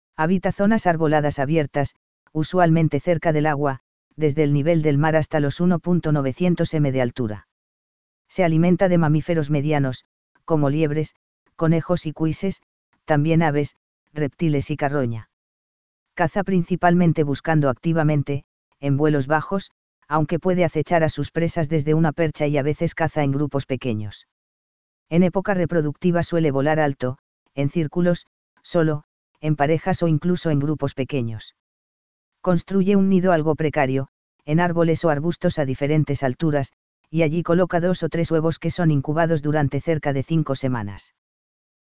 Parabuteo unicinctus unicinctus - Gavilán mixto